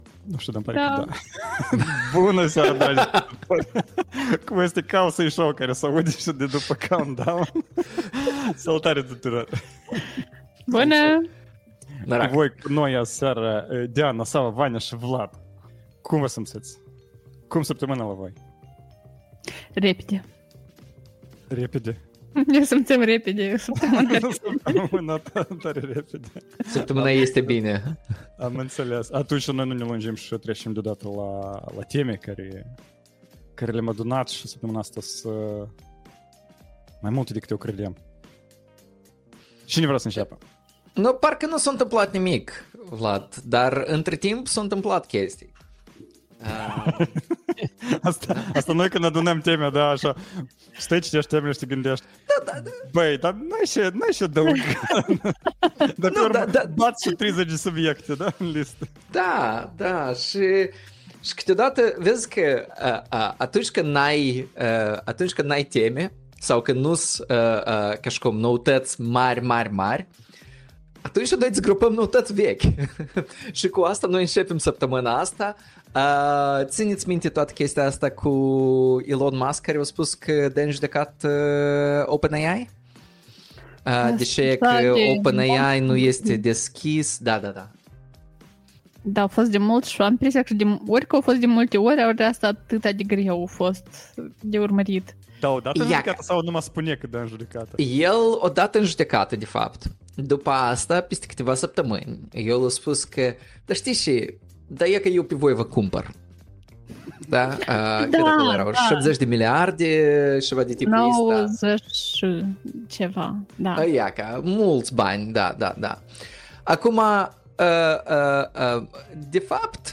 September 19th, 2025 Live-ul săptămânal Cowsay Show.